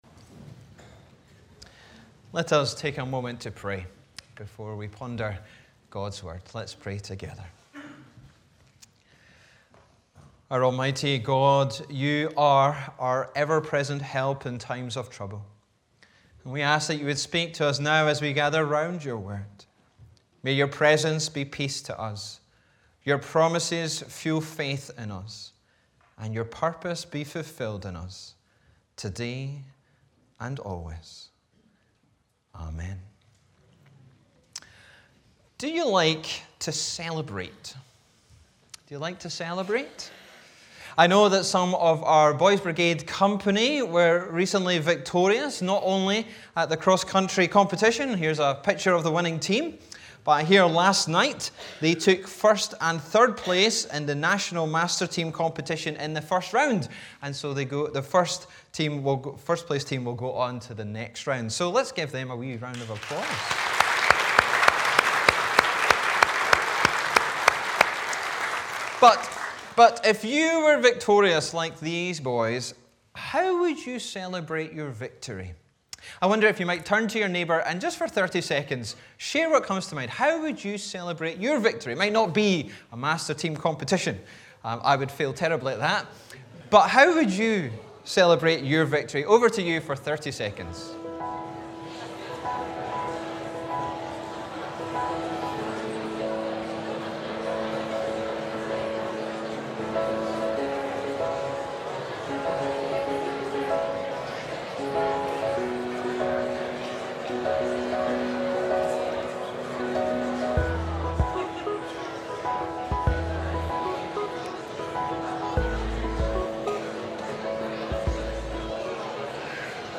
Bible references: Romans 8:31-35, 37-30 & Mark 15:16-20, 33-34, 37-39 Location: Brightons Parish Church Show sermon text Sermon keypoints: - More than conquerors - Loved by the living Jesus